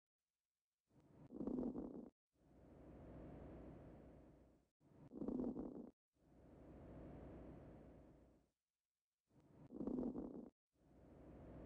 Crepitantes_moderados.mp3